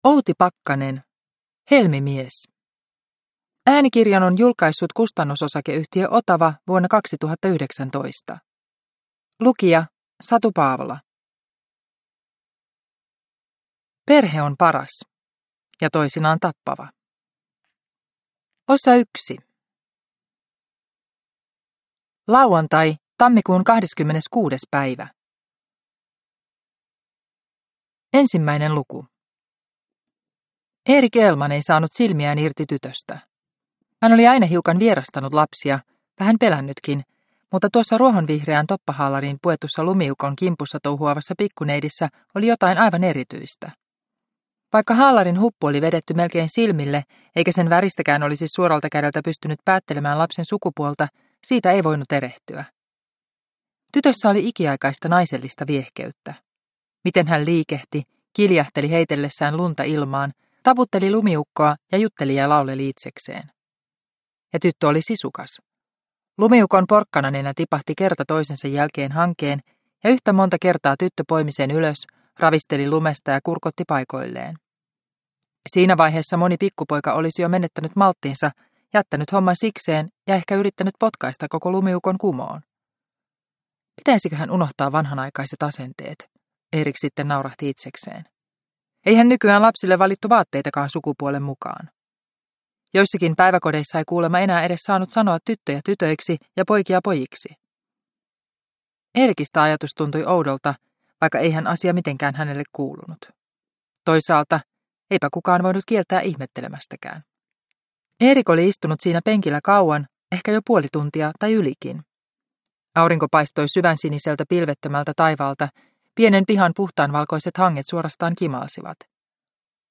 Helmimies – Ljudbok – Laddas ner